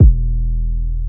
TM88 808Kick.wav